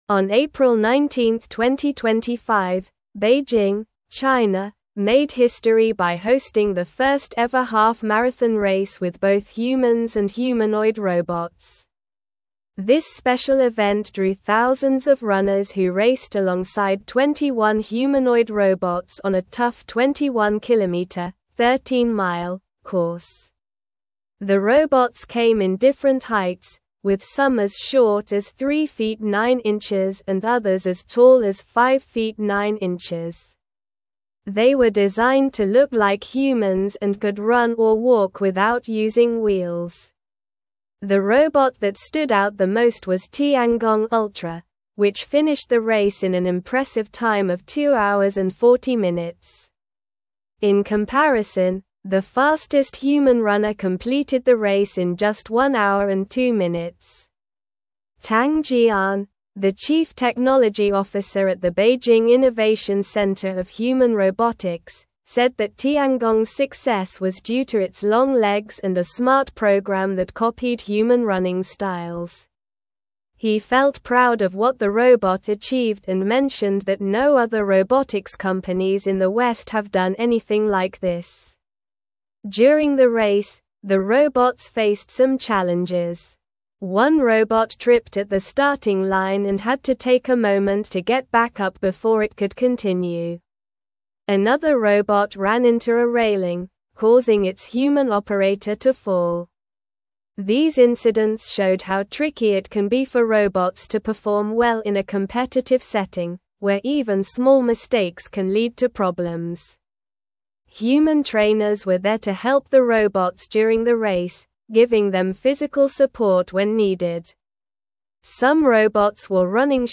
Slow
English-Newsroom-Upper-Intermediate-SLOW-Reading-China-Hosts-Historic-Half-Marathon-with-Humans-and-Robots.mp3